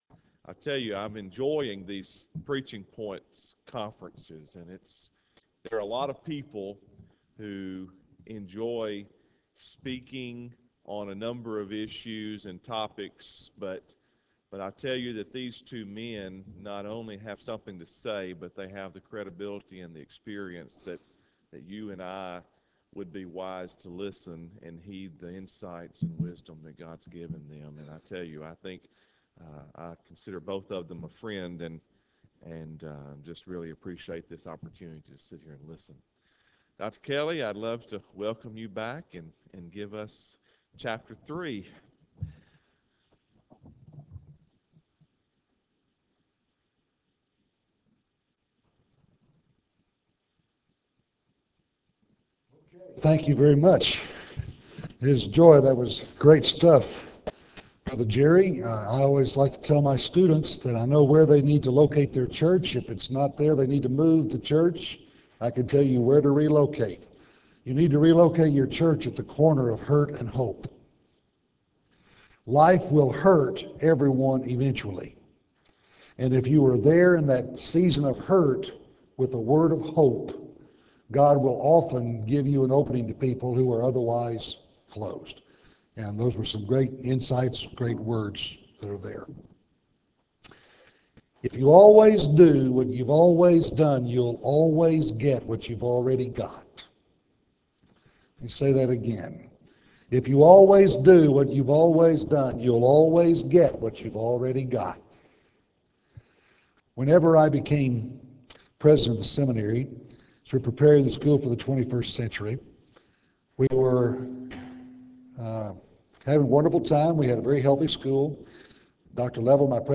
Address: "The Big Challenge"